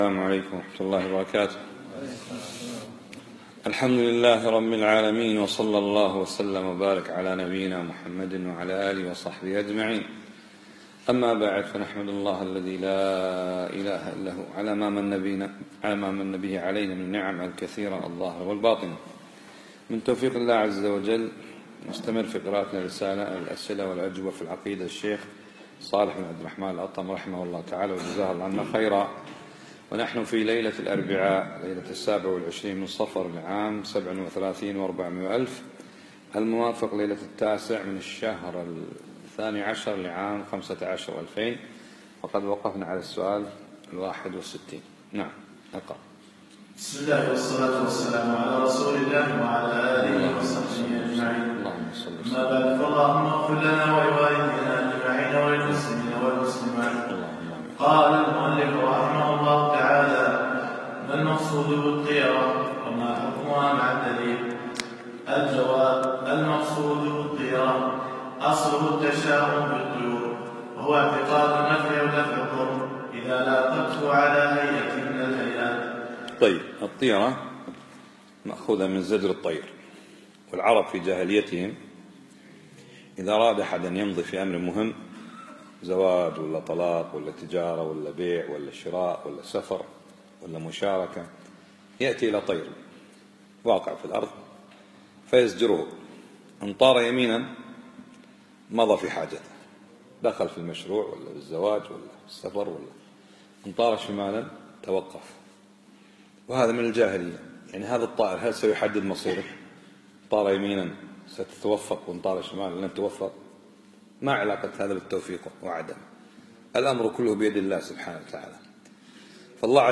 الدرس التاسع عشر